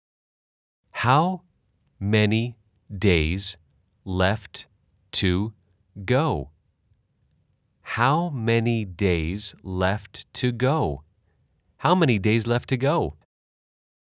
/ 하우 매니 데이즈 / 레에프트고우/